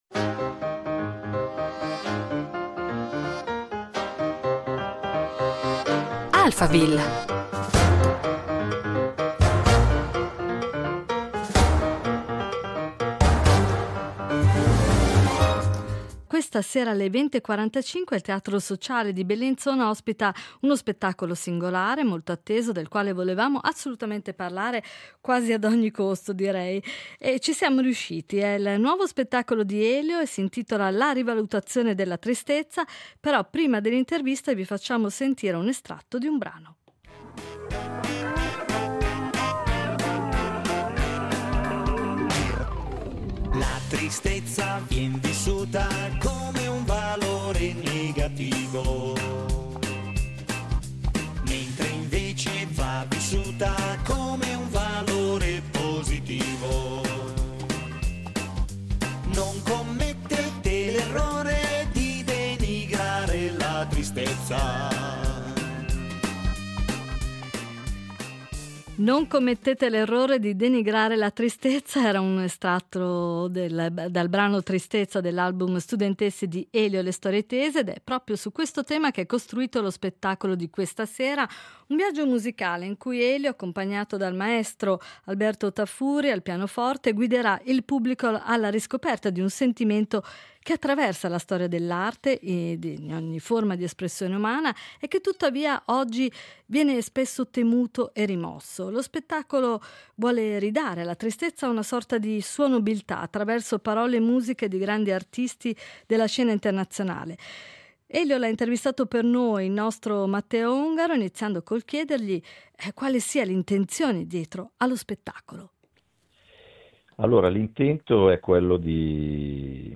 L’intento è quello di restituire alla tristezza nobiltà e necessità attraverso le parole e le note di alcuni grandi artisti della tradizione musicale italiana e internazionale. A raccontare il progetto, ai microfoni di Alphaville, lo stesso Elio.